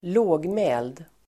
Ladda ner uttalet
Uttal: [²l'å:gmä:ld]